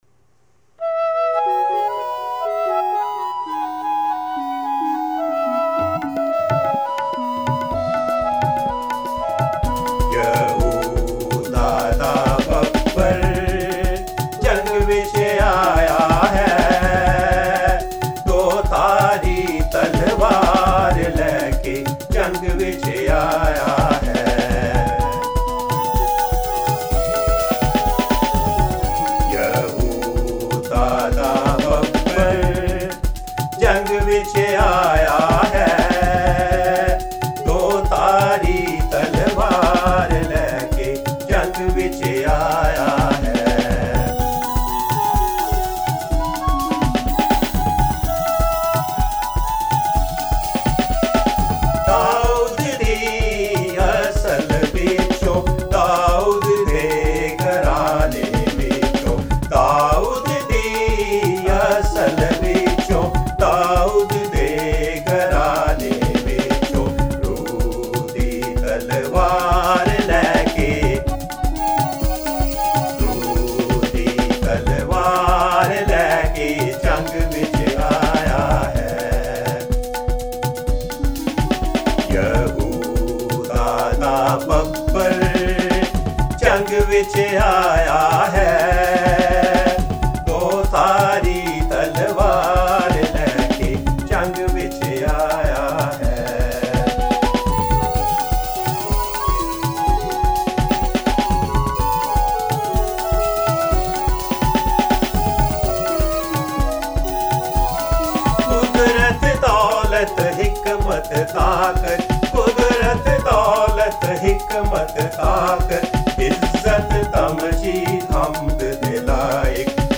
Christian devotional songs
vocals